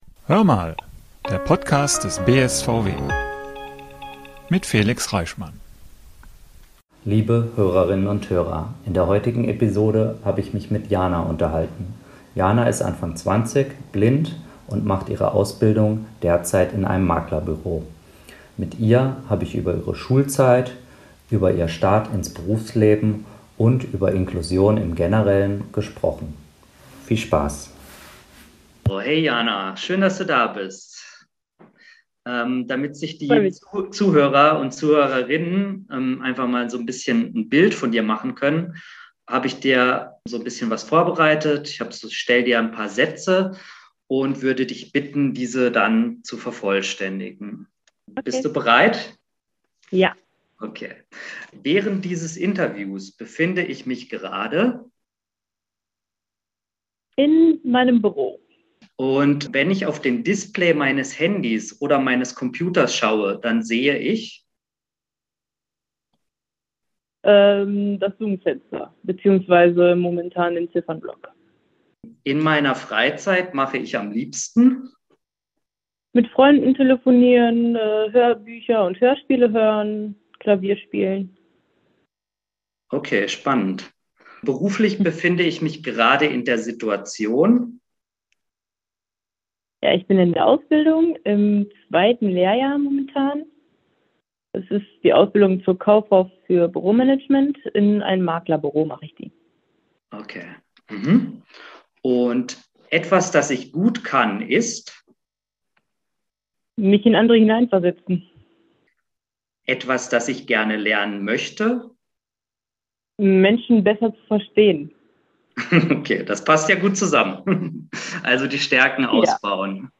In einem abwechslungsreichen Gespräch hat Sie uns über Ihre Schulzeit und über das Zusammenleben mit Ihren sehenden Geschwistern erzählt.